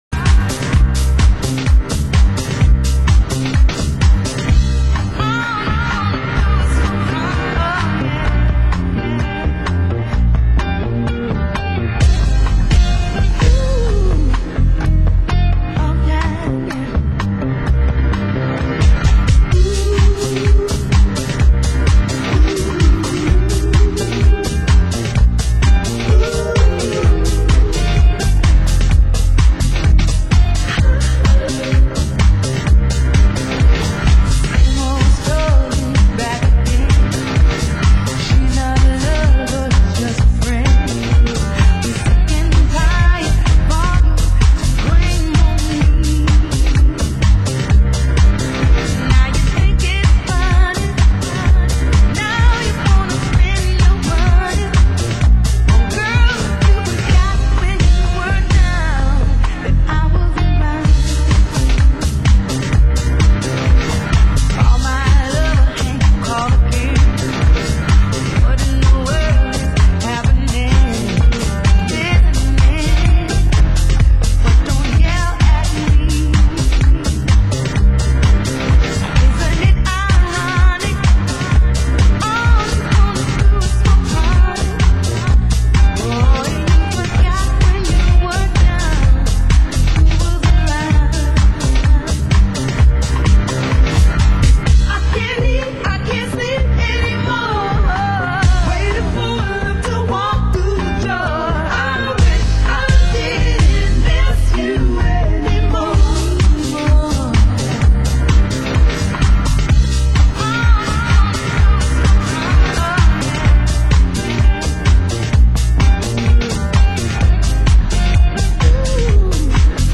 Format: Vinyl 12 Inch
Genre: Deep House